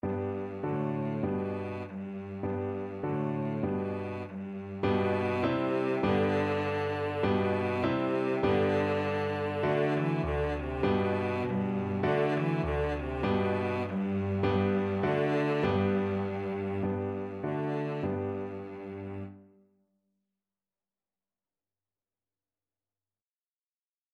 Cello
Traditional Music of unknown author.
4/4 (View more 4/4 Music)
G major (Sounding Pitch) (View more G major Music for Cello )